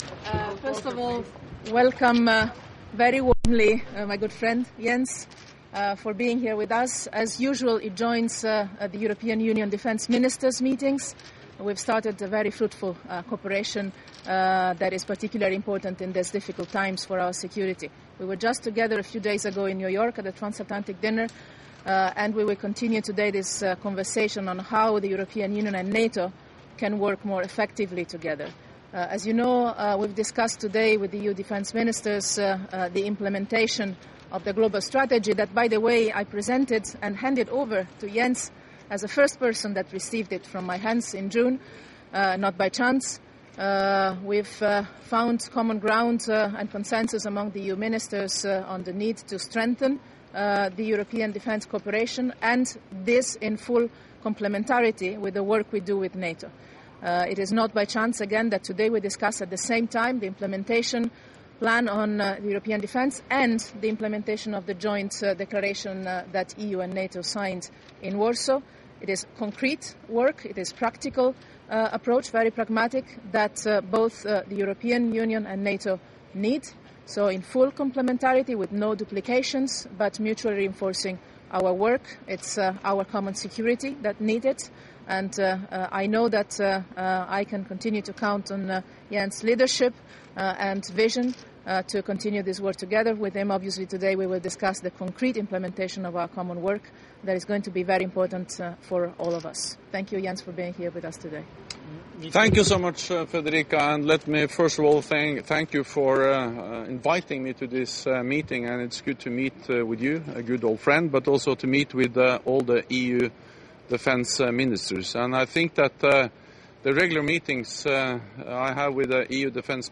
Joint press statement by NATO Secretary General Jens Stoltenberg and High Representative of the European Union for Foreign Affairs and Security Policy Federica Mogherini